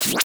UIClick_Menu Back Cancel Whoosh.wav